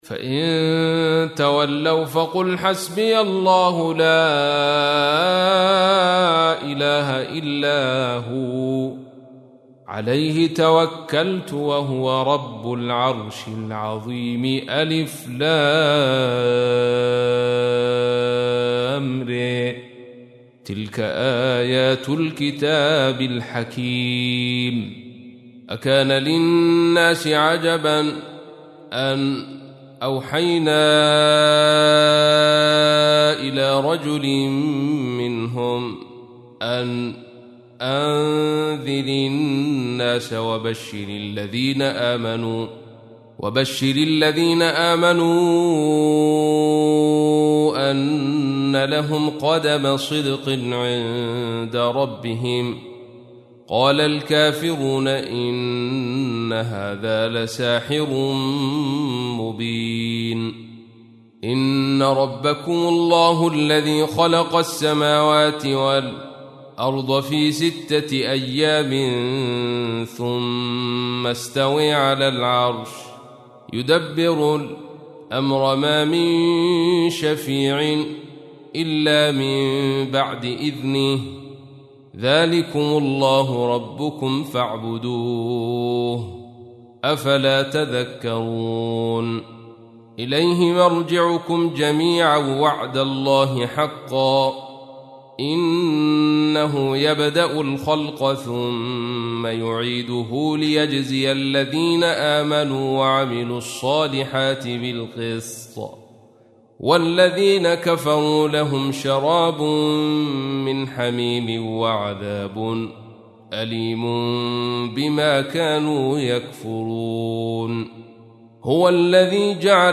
تحميل : 10. سورة يونس / القارئ عبد الرشيد صوفي / القرآن الكريم / موقع يا حسين